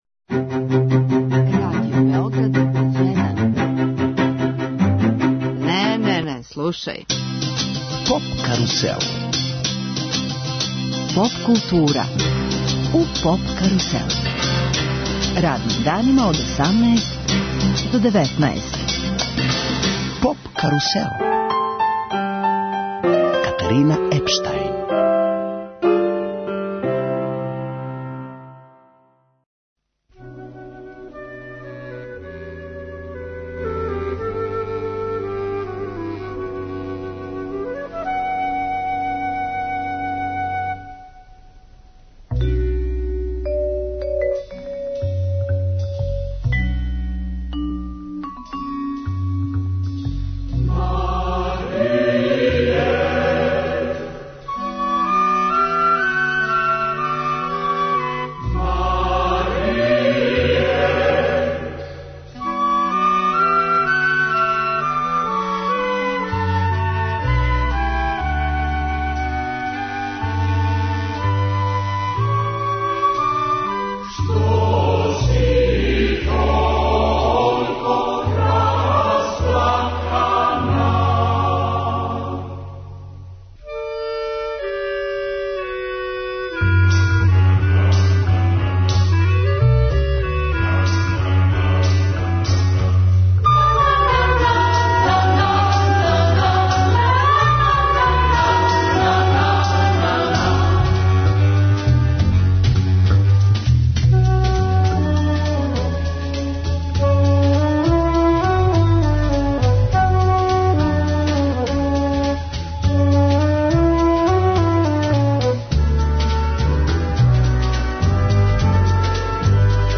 У оквиру турнеје Радио Београда емисија се емитује уживо из Неготина.